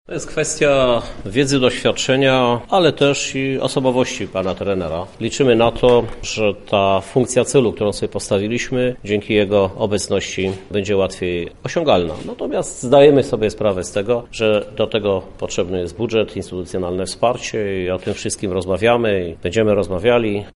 Mówi prezydent Lublina.